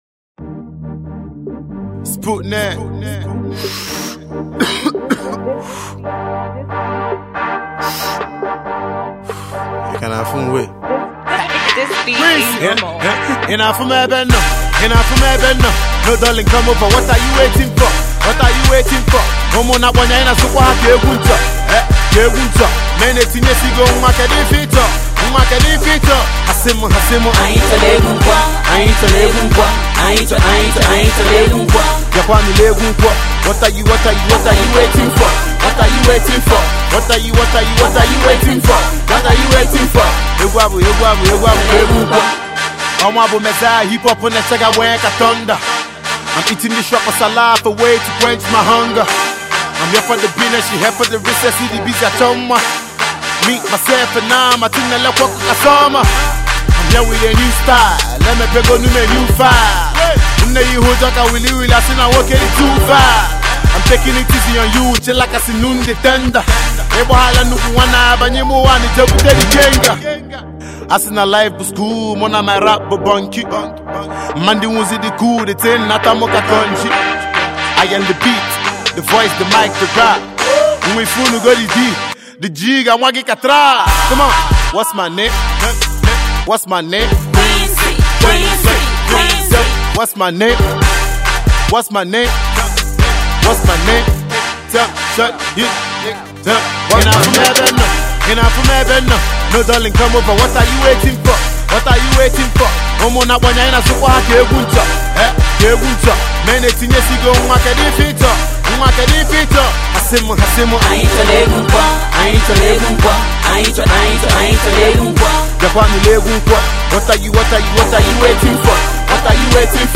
Igbo Music, indigenous Hip-Hop
Indigenous Igbo MC
Street Banger